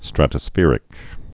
(strătə-sfîrĭk, -sfĕr-)